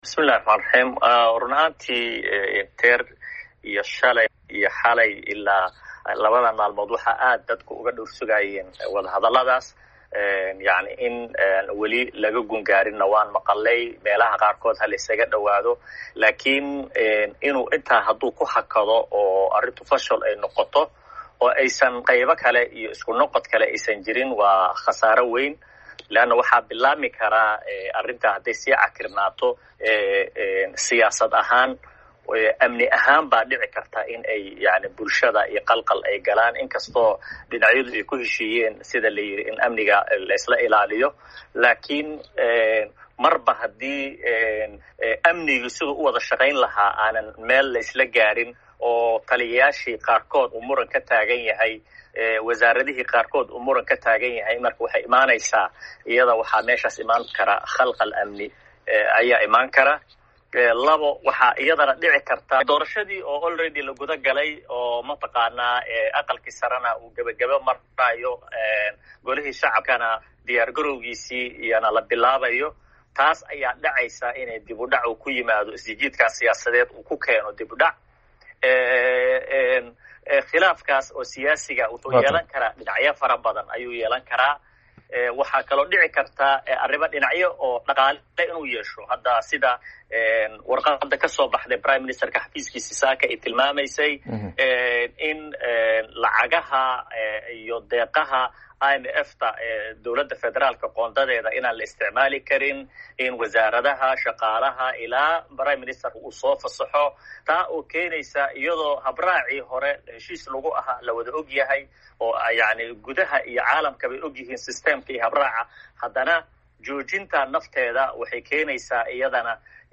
Wareysi: Dhibaatooyinka ka dhalan kara khilaafka madaxda